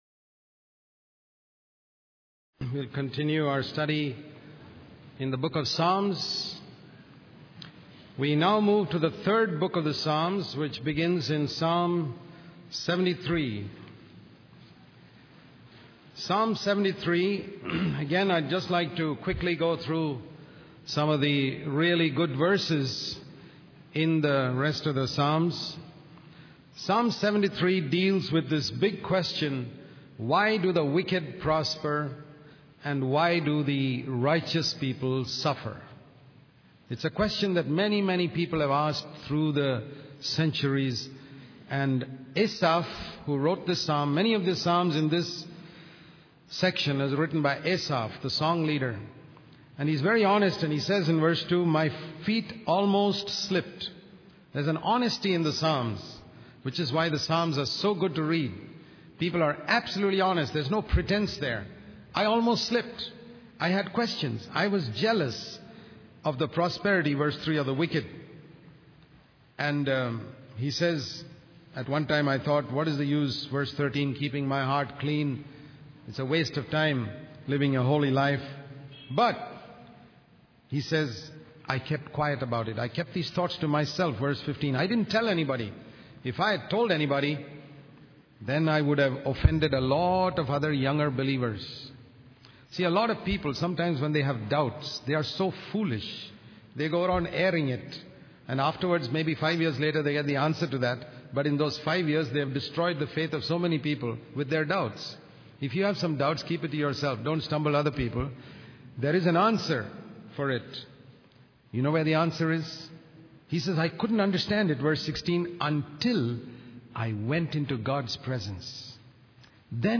In this sermon, the preacher emphasizes the importance of praising God and acknowledging His blessings in our lives. The sermon is based on Psalm 113, which is a psalm of praise.